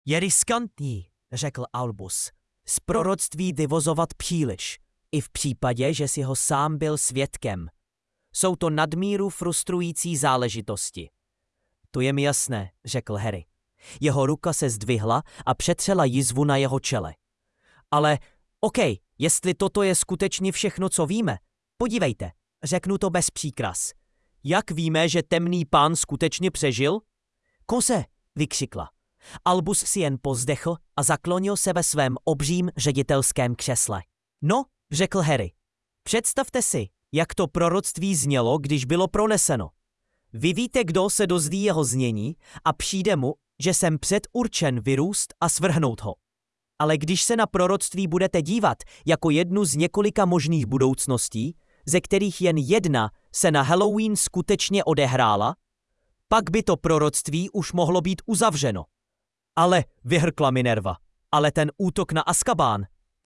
takhle, jak to teď je, už je to imho použitelné na poslouchatelnou audioknihu, včetně hereckého výkonu (!), akorát si v češtině vtipně šlape na jazyk s Ř (a to jen některé pokusy) openai-fm-ballad-audio.mp3